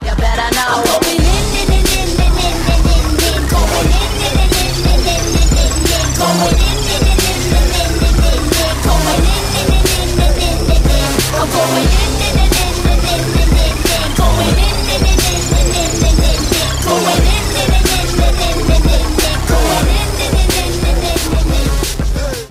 • Качество: 192, Stereo
Хороший реп с женским вокалом, как будто завили мотор)